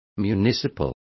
Complete with pronunciation of the translation of municipal.